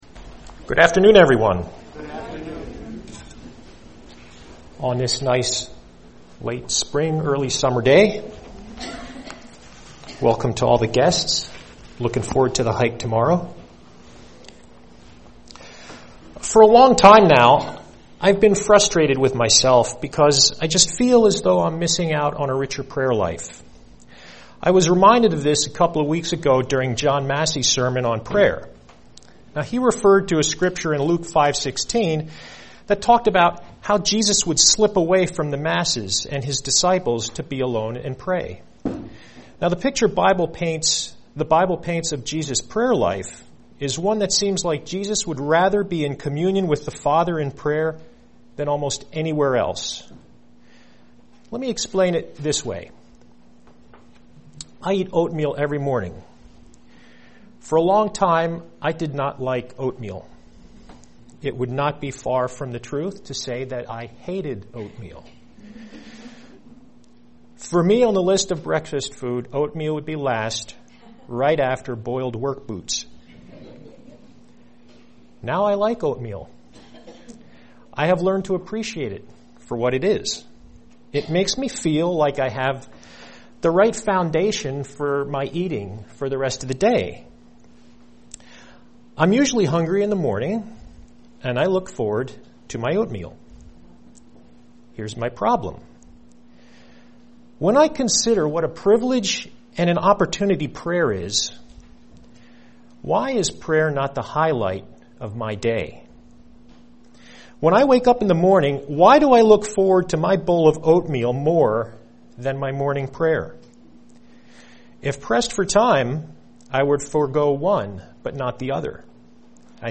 UCG Sermon Studying the bible?